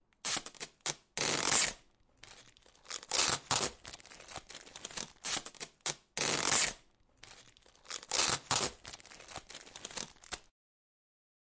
描述：为（未来）大提琴无伴奏的套曲中的哀歌，在柔和的合成器Zebra中产生了一种遗憾的感觉.
标签： 合成 大提琴 电子 感叹 谐振
声道立体声